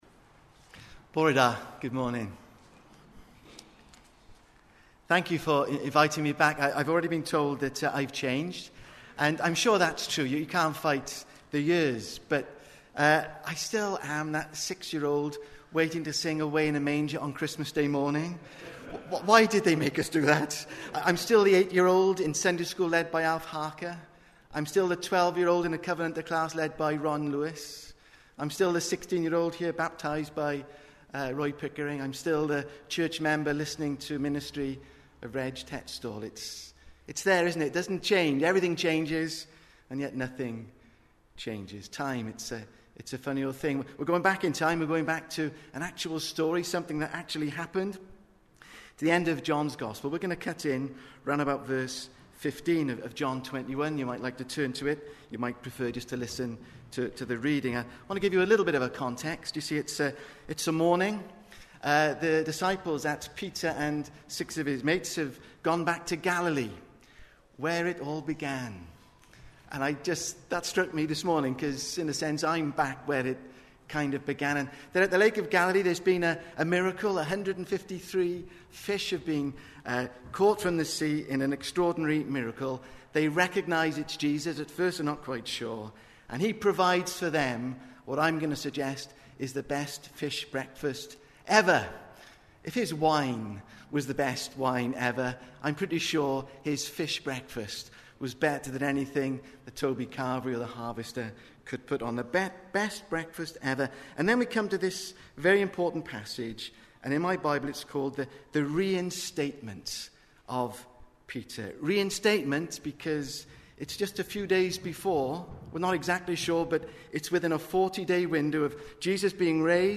15 Service Type: Sunday Morning Bible Text